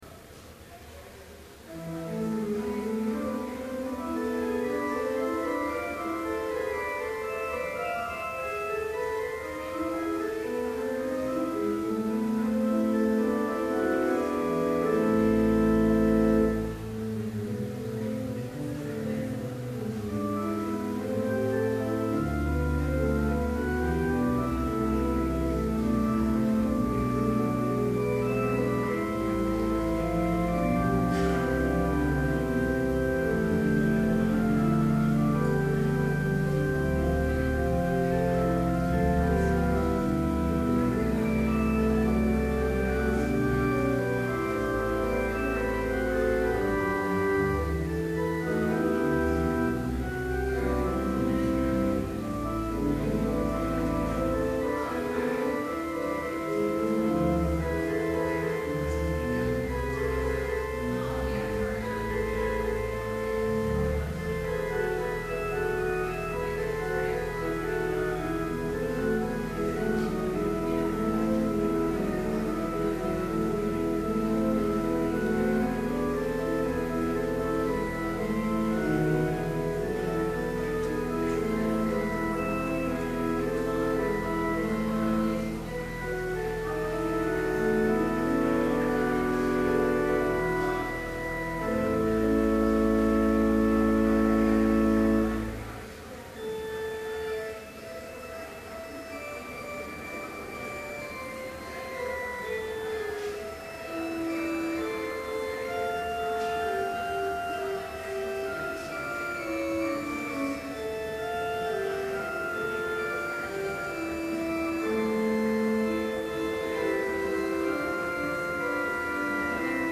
Complete service audio for Chapel - January 23, 2012